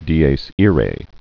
(dēās îrā)